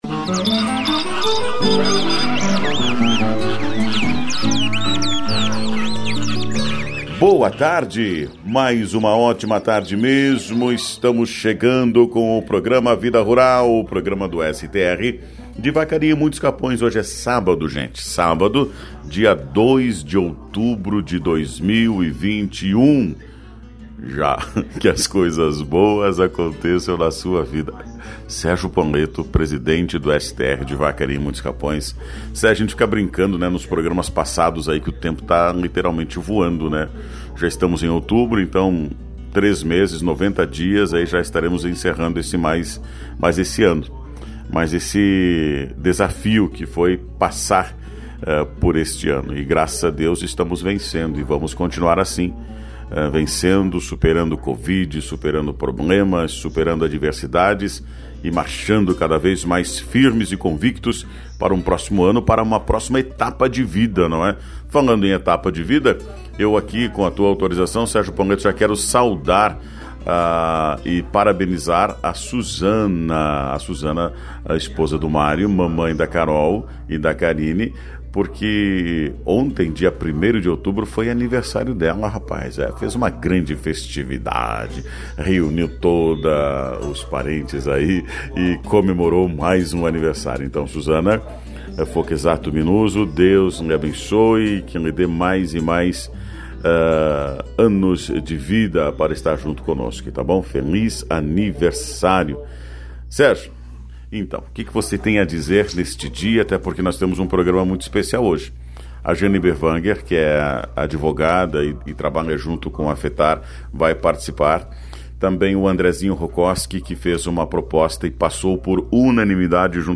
Programa Vida Rural 02 de outubro 2021, informativo do STR de Vacaria e Muitos Capões